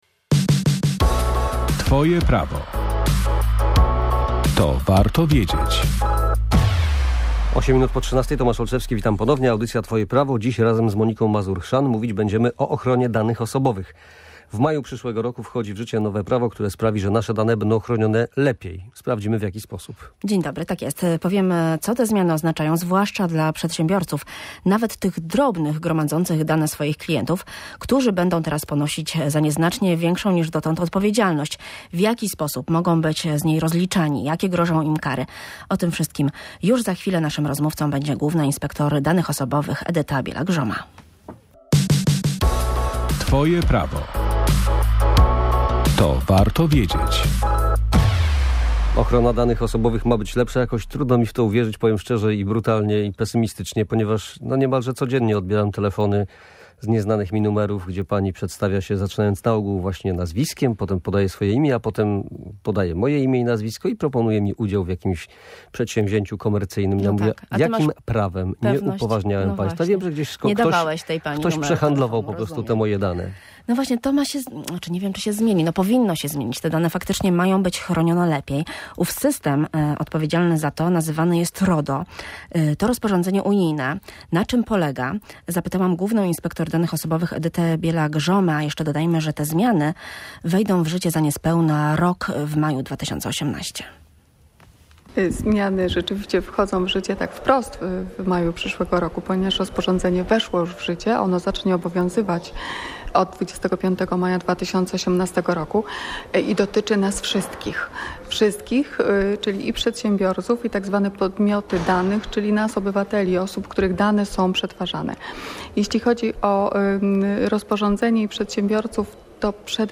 Nowy system, nazywany RODO, to rozporządzenie unijne. Zmiany wchodzą w życie w maju 2018 roku i dotyczą nas wszystkich – tłumaczy Główna Inspektor Danych Osobowych Edyta Bielak-Joma.